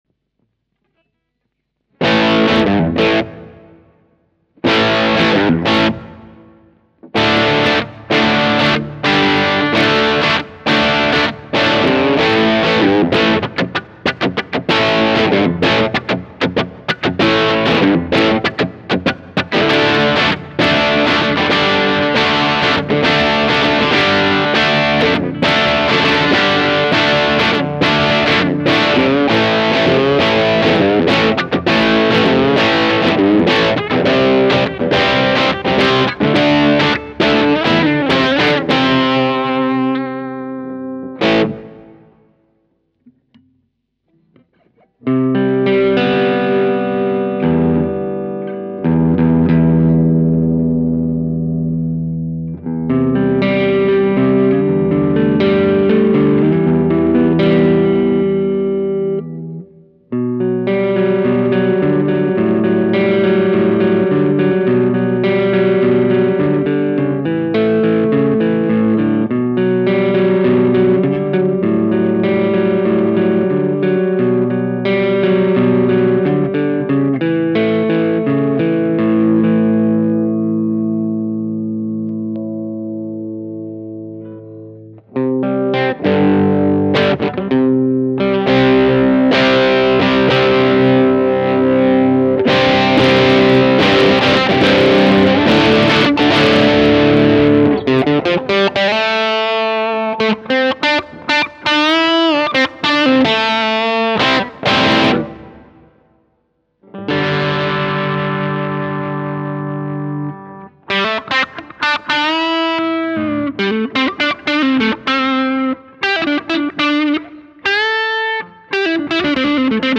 Sur l'épiphone, la gibson et la fender !!!
Voici celui ci enregistré avec la gibson explorer. Y'a un peu de jump dedans (je m'échauffe dessus :p )